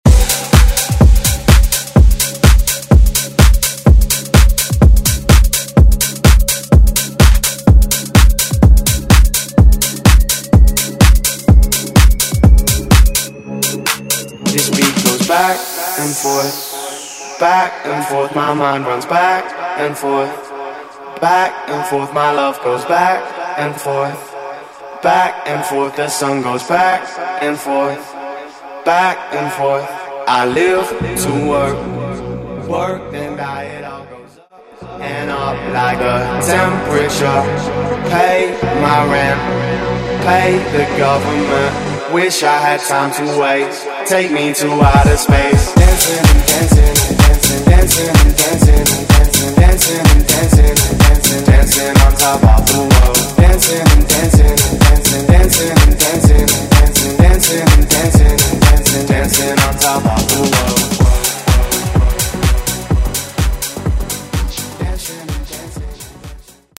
Genres: DANCE , RE-DRUM , TOP40 Version: Clean BPM: 132 Time